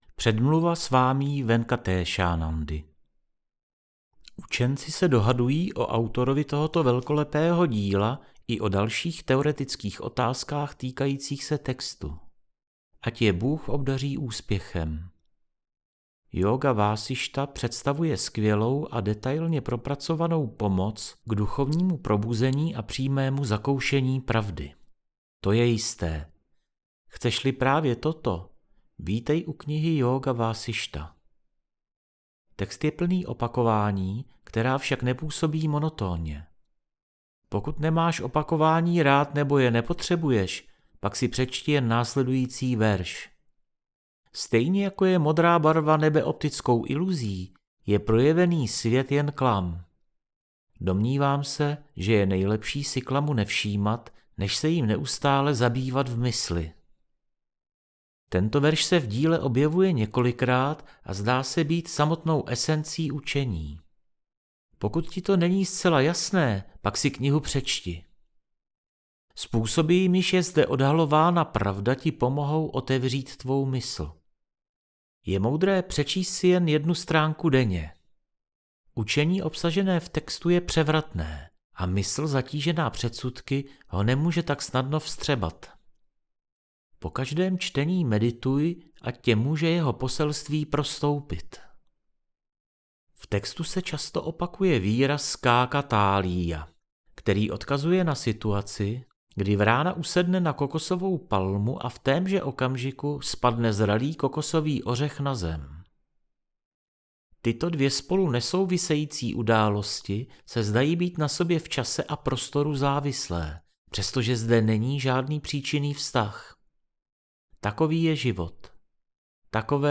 SAMHITÁ – Jóga_Vásištha – Audiokniha - Úvod